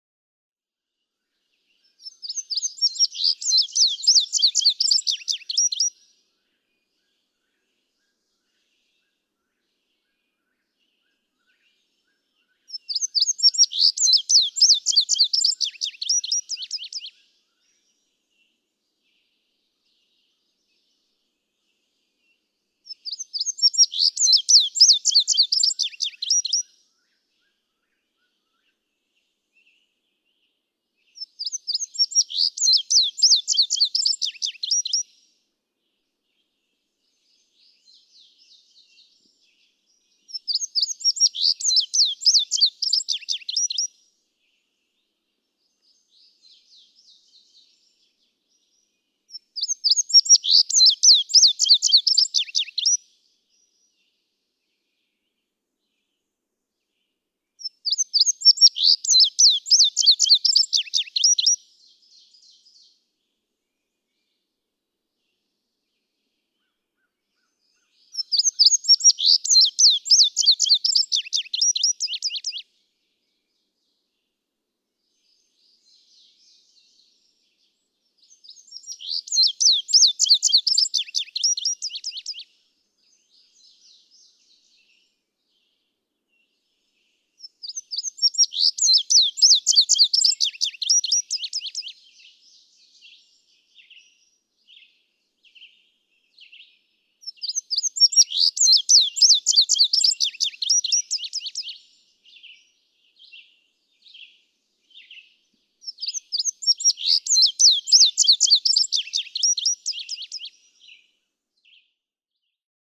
Tye River Gap, Blue Ridge Parkway, Virginia.
♫205, ♫206—longer recordings from those two neighbors
206_Indigo_Bunting.mp3